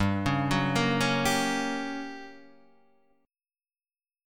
G Minor Major 7th Flat 5th